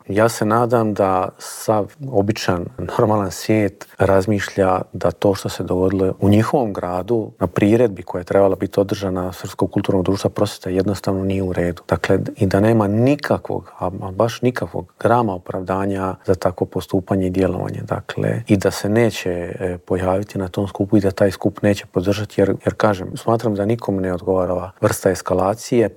Predsjednik Srpskog narodnog vijeća Boris Milošević u Intervjuu tjedna Media servisa poručuje da je teško objasniti kako je iz jedne male kulturne priredbe ovo preraslo u tako veliki problem.